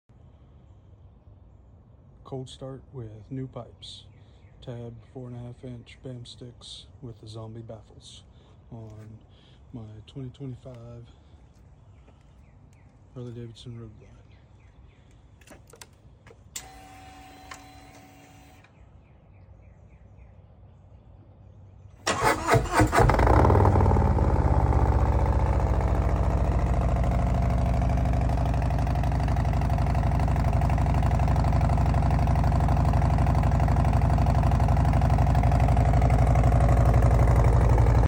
Let’s try this again…cold start sound effects free download
Let’s try this again…cold start (without background music). 2025 Road Glide TAB 4.5 bam sticks and zombie baffles.